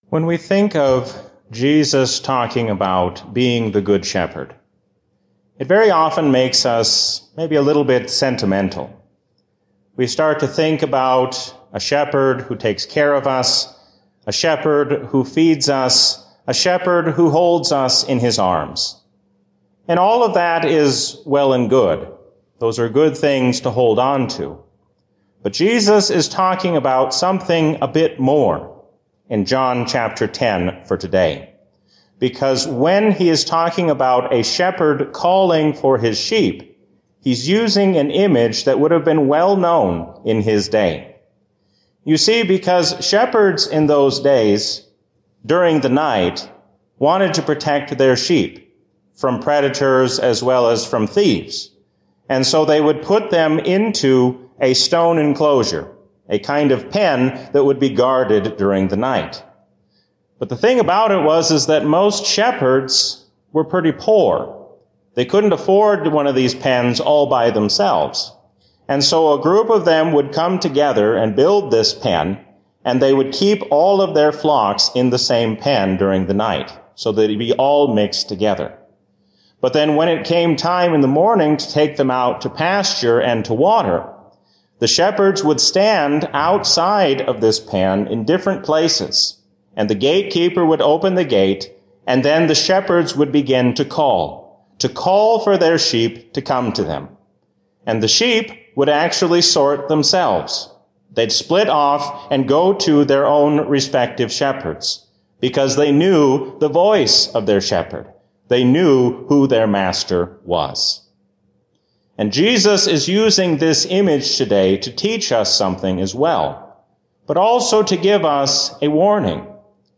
A sermon from the season "Easter 2024." Do not give in to the allure of this world, but stand fast, knowing that God will soon bring all evil to an end.